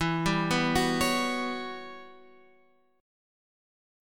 E Minor 6th Add 9th